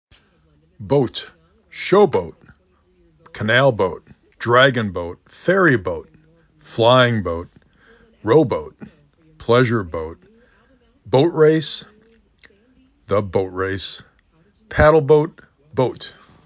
b O t